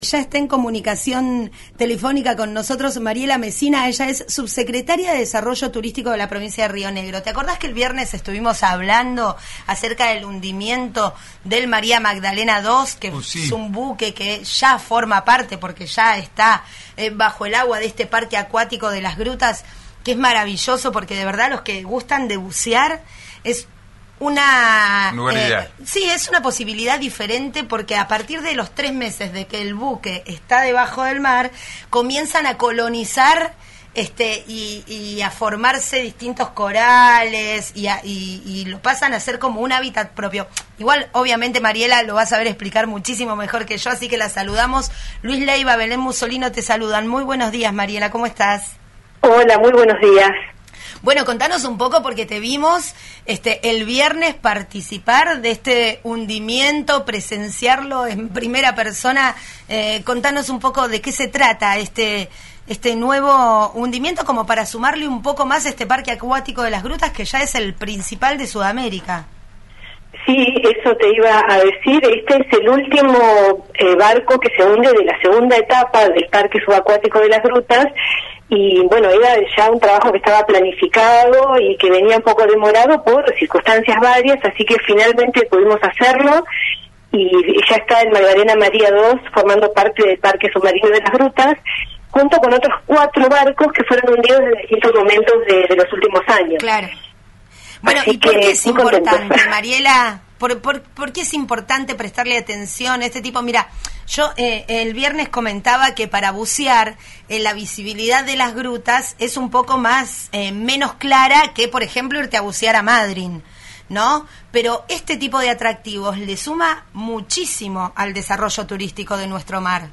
Escuchá a Mariela Messina, Subsecretaria de Desarrollo Turístico de Río Negro, en RÍO NEGRO RADIO: